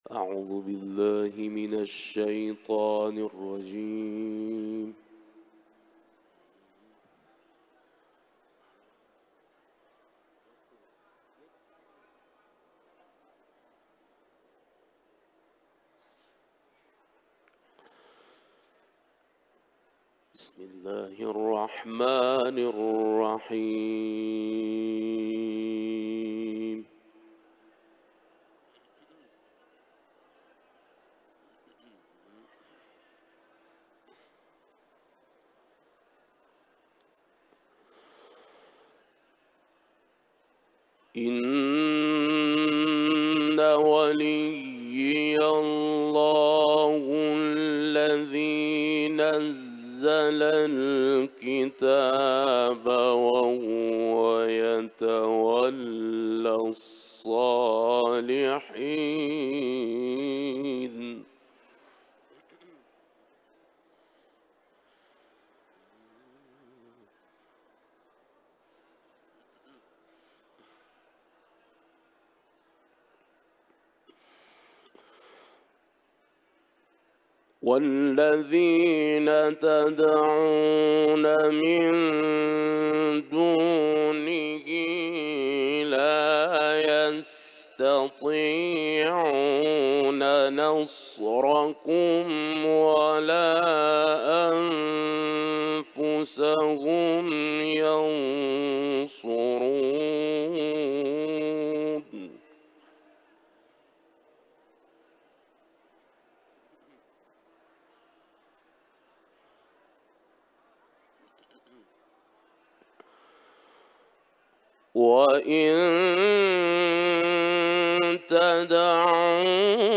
Тегҳо: ҳарами Разавӣ ، Остони Қудси Разавӣ ، Эрон ، Тиловати Қуръон ، тиловати маҷлисӣ